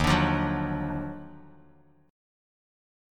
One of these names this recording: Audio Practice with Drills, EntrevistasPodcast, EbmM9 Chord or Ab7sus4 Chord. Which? EbmM9 Chord